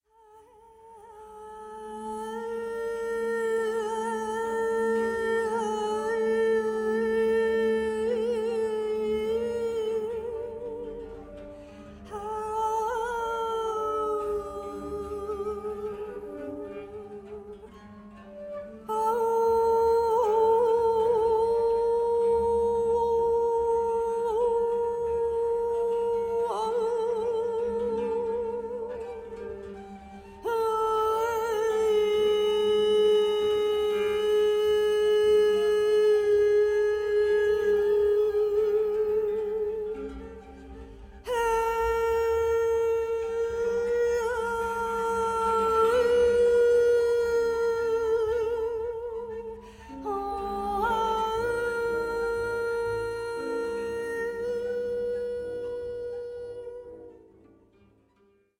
double bass
voice
koto, 17 string bass koto